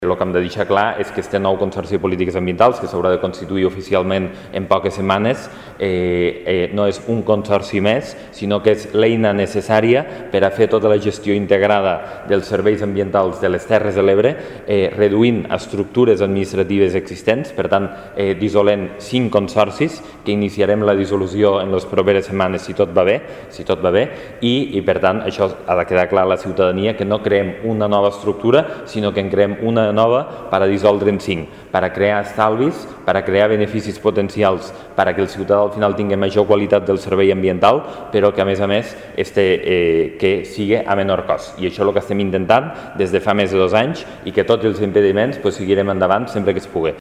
(tall de veu) El president de l'ens comarcal, Lluís Soler, explicant l'aprovació dels Estatuts i la constitució del COPATE
lluis_soler_-_copate.mp3